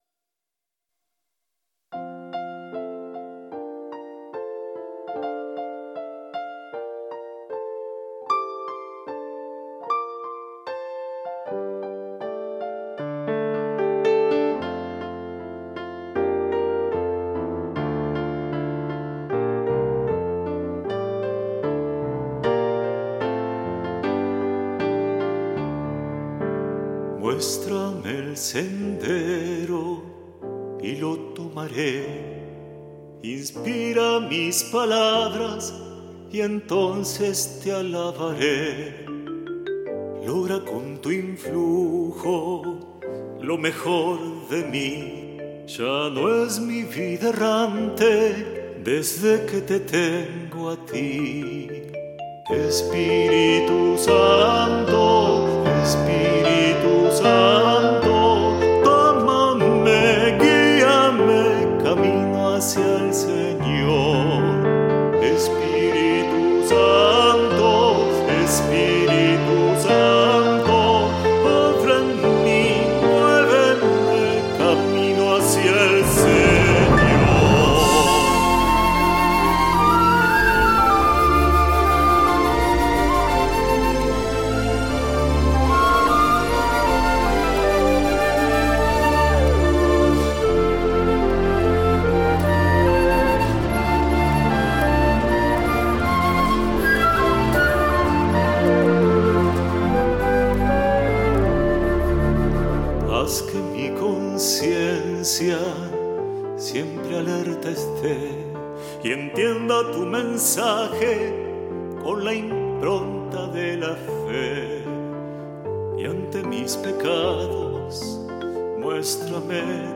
Canciones Autores